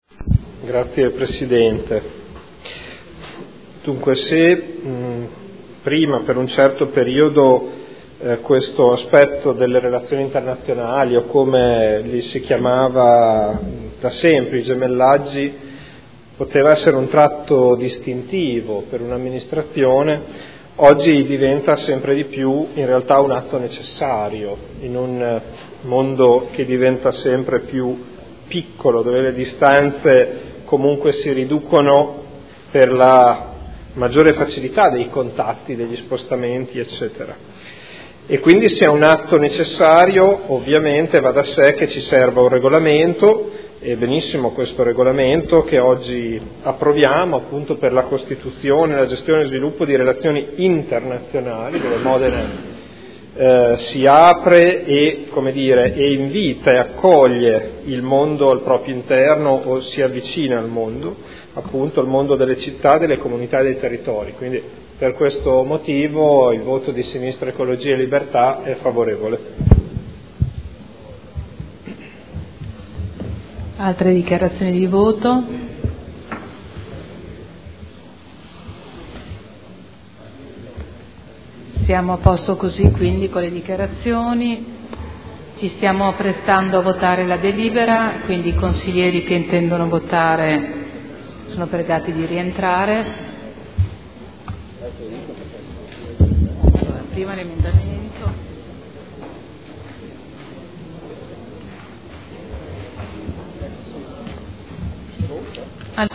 Proposta di deliberazione: Regolamento per la costituzione, la gestione e lo sviluppo di relazioni internazionali con città, comunità e territori. Dibattito